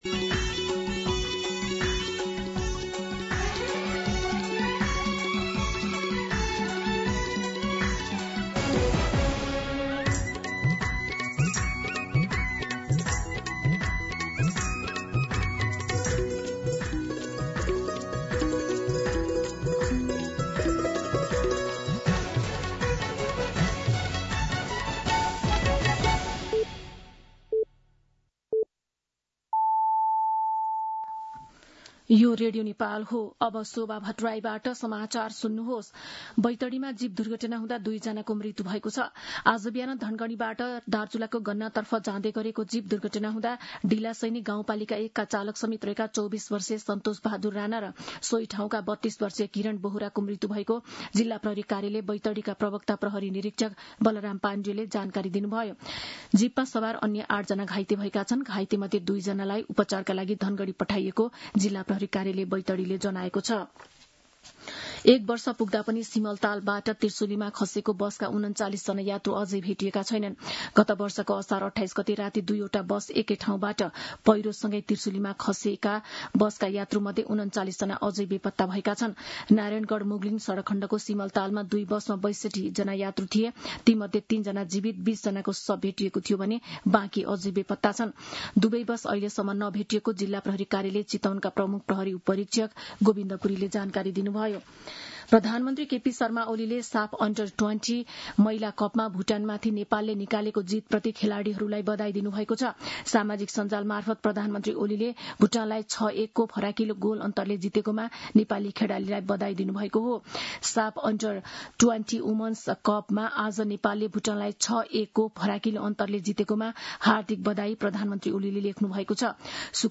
मध्यान्ह १२ बजेको नेपाली समाचार : २८ असार , २०८२
12-pm-Nepali-News.mp3